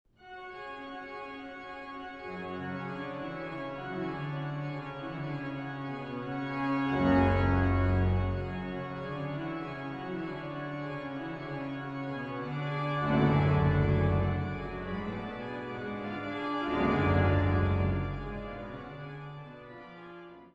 Walcker-Orgel St. Jakobus zu Ilmenau